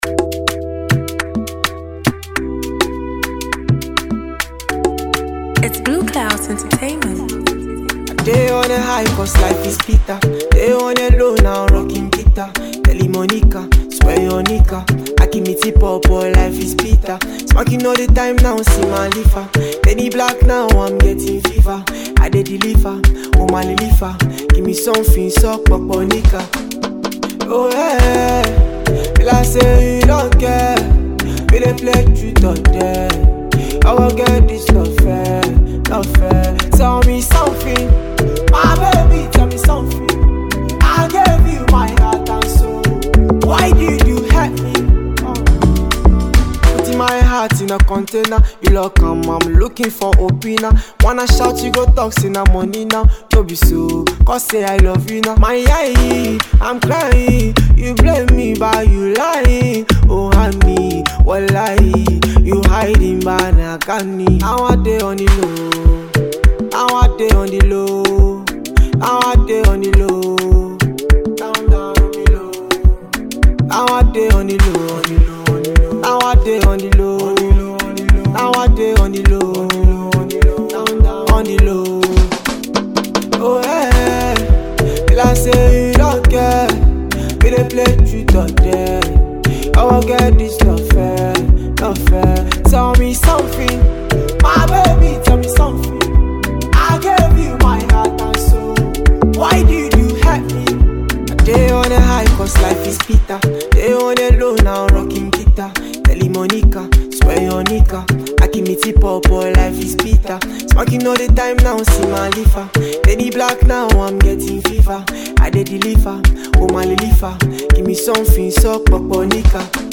a sensational Ghanaian singer
an easy-to-sing song
Afrobeat music